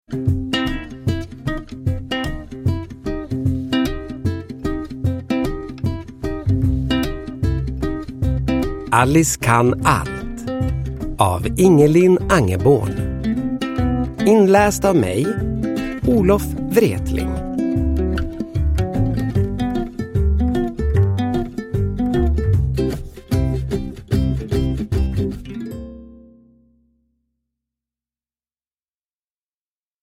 Alice kan allt! – Ljudbok – Laddas ner
Uppläsare: Olof Wretling